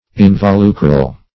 Search Result for " involucral" : The Collaborative International Dictionary of English v.0.48: Involucral \In`vo*lu"cral\, a. [Cf. F. involucral.]
involucral.mp3